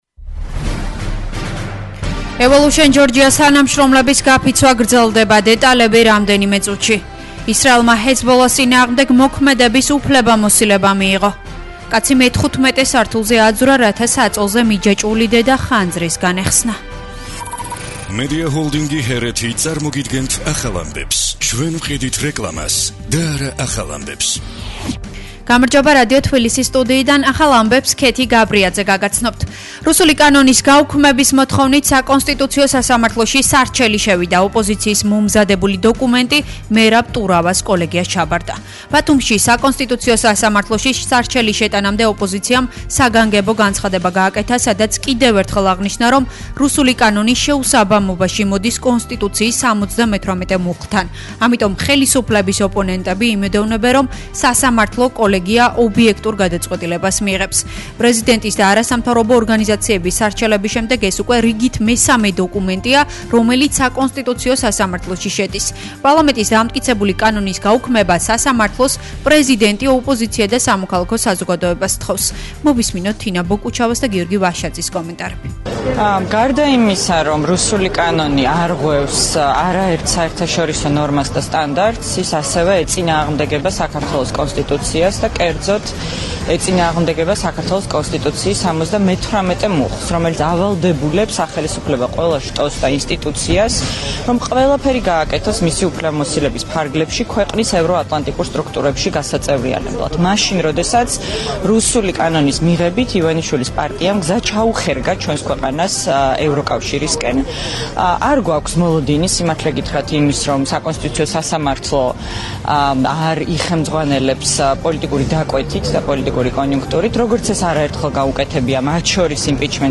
ახალი ამბები12:00 საათზე